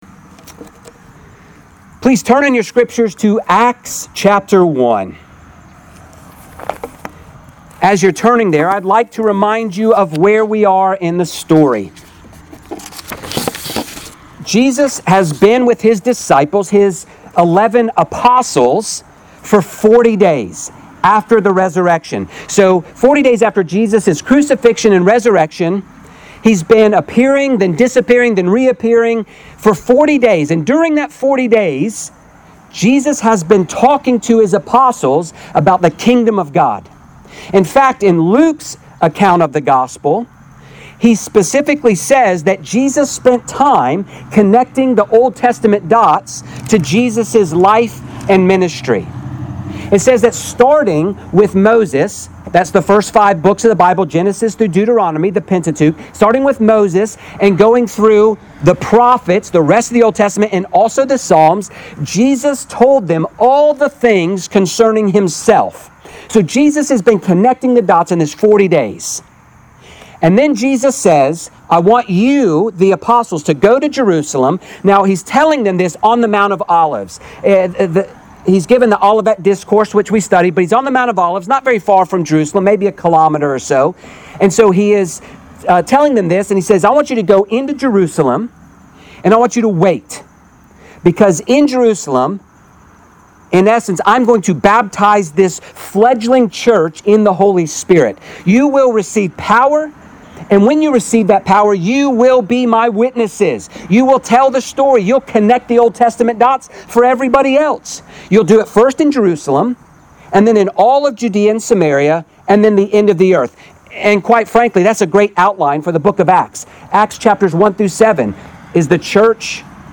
Grace Presbyterian Church, PCA Sermons